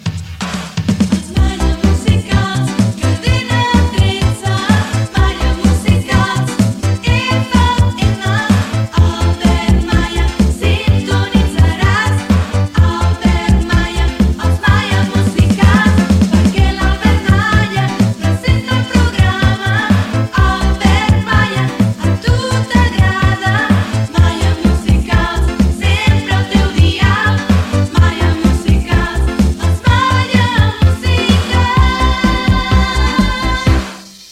Jingle del programa Gènere radiofònic Musical